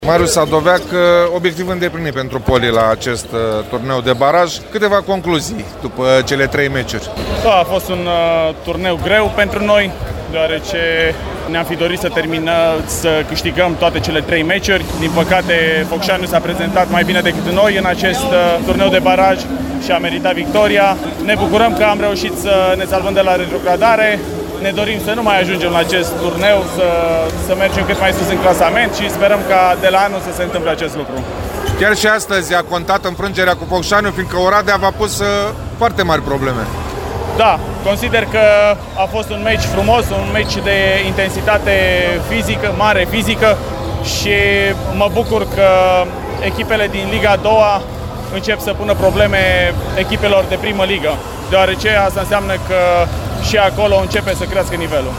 a fost intervievat